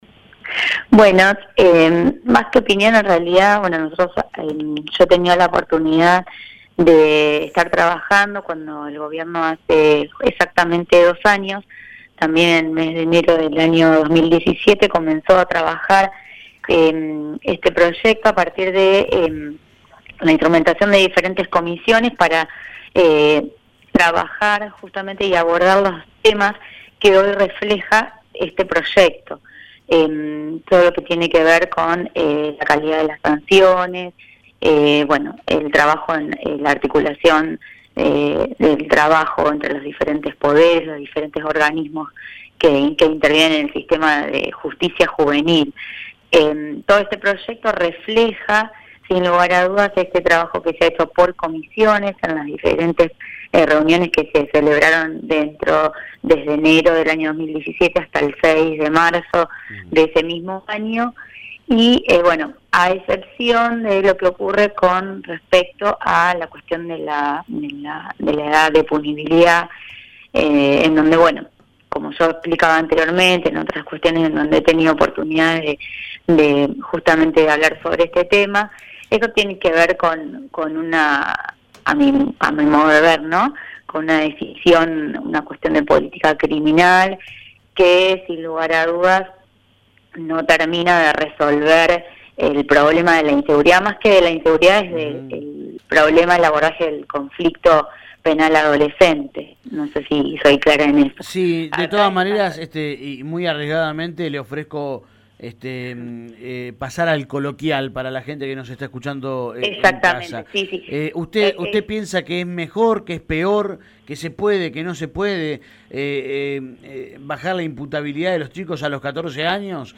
[Testimonio emitido en el programa Nada personal, que emite DE LA BAHÍA, de lunes a viernes, de 7 a 9]